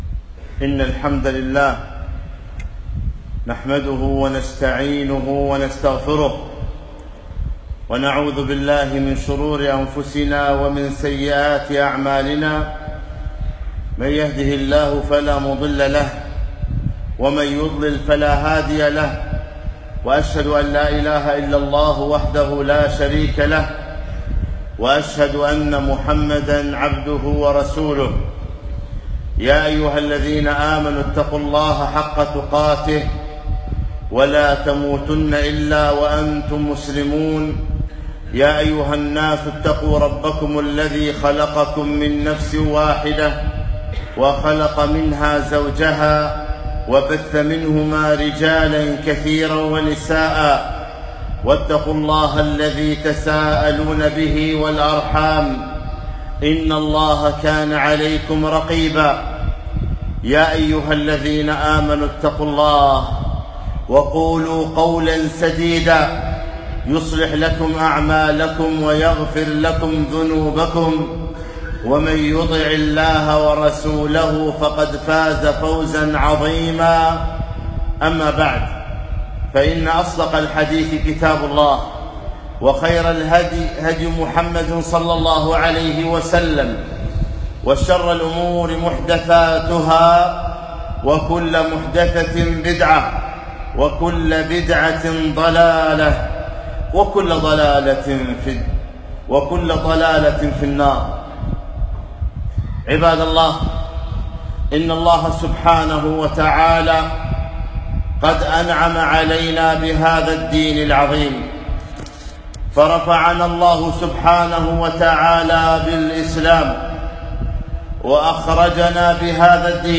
خطبة - خطورة الأستهزاء بالدين